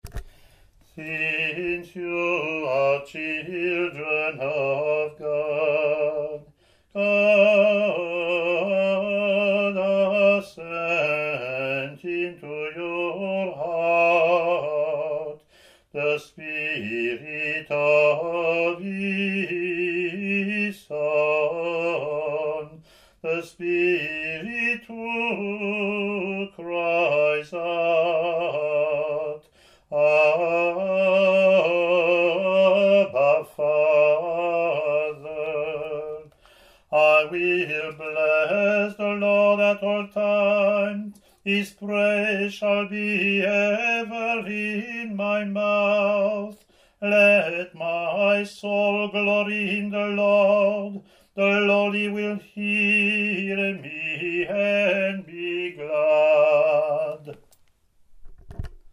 Click to hear Communion (
English antiphon – English verseYear A&C Latin antiphon + verses, Year B Latin antiphon + verses)